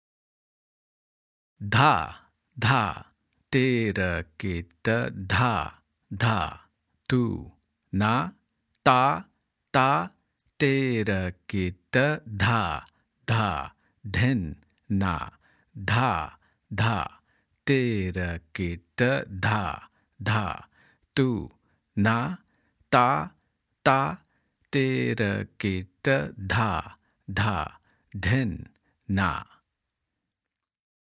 1x Speed (slow) – Spoken